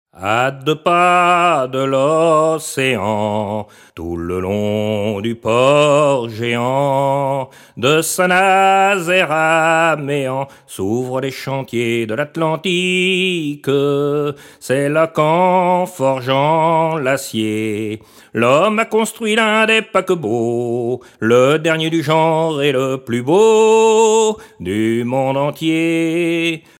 Genre strophique
Catégorie Pièce musicale éditée